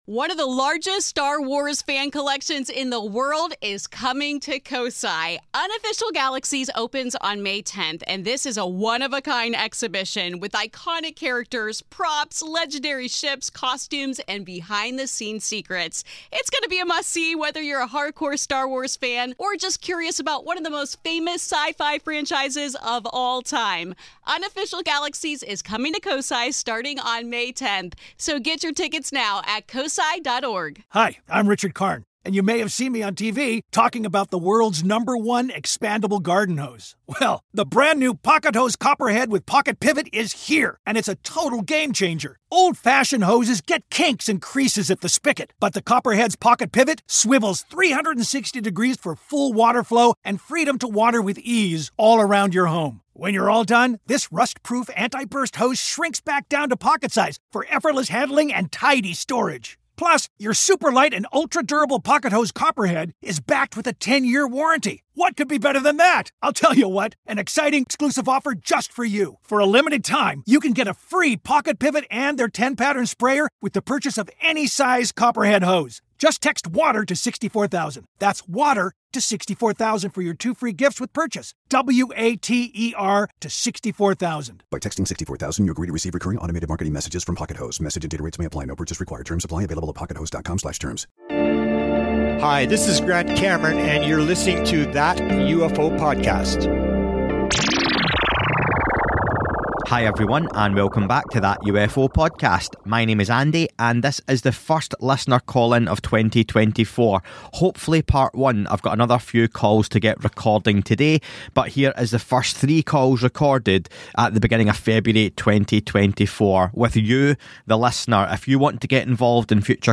Listener Call-in Feb '24, Pt.1